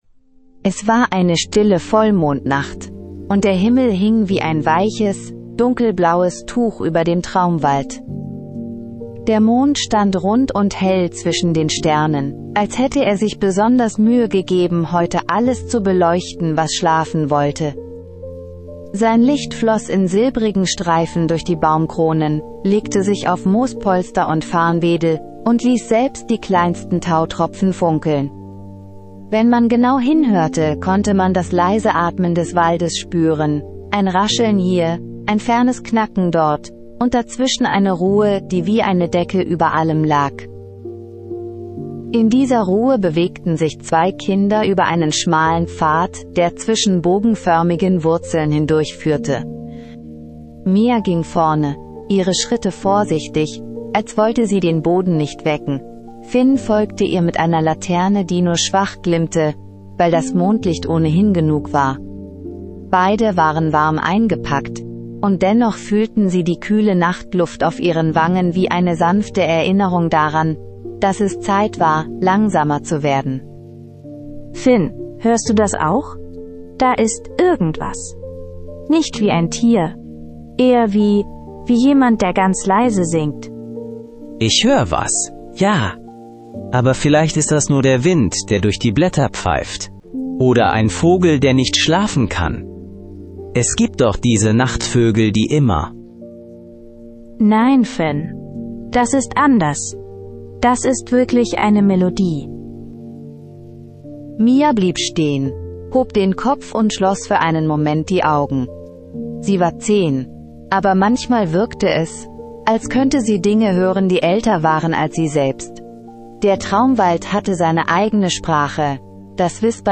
Magisches Einschlaf-Hörspiel für Kinder